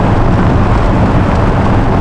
GRASS_E.WAV